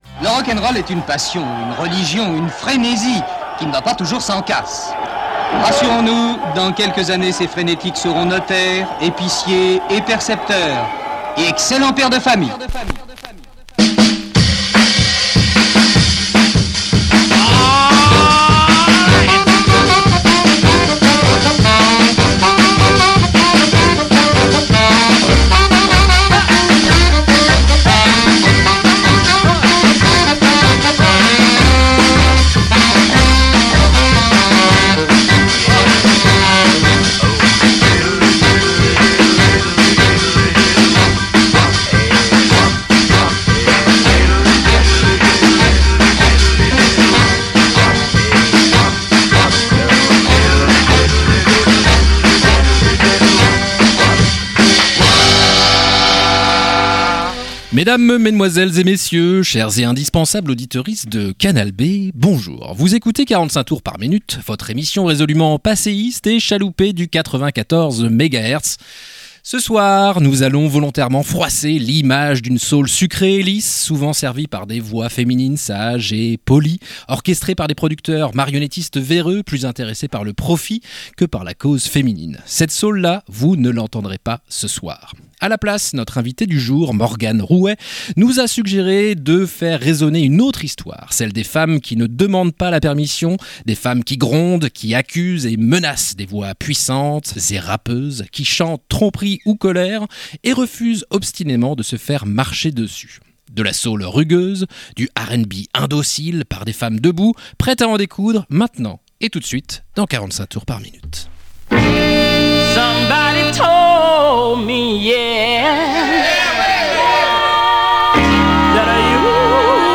Rock’n’roll, Mambo, RnB, Garage, Doo Wop & Cha Cha Cha